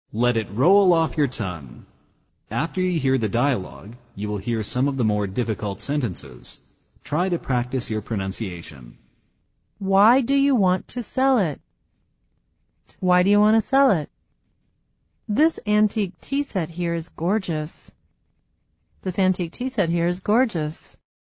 《发音练习》